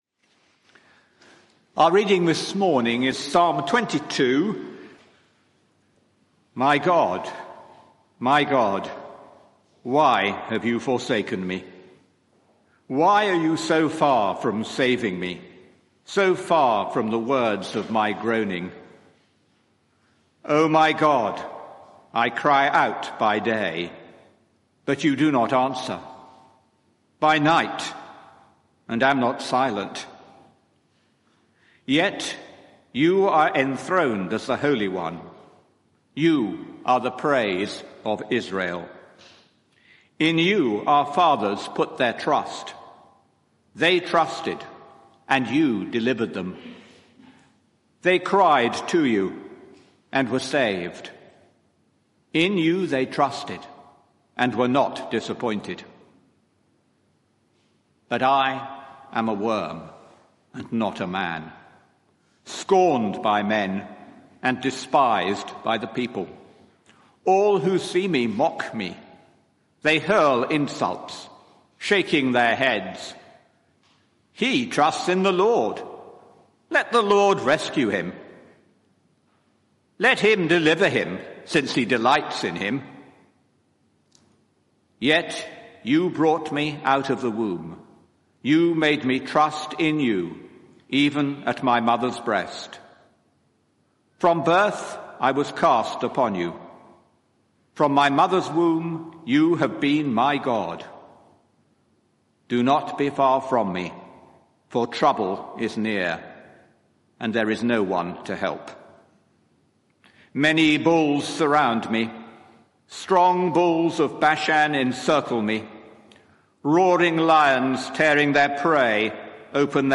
Media for 11am Service on Sun 06th Aug 2023 11:00 Speaker
Sermon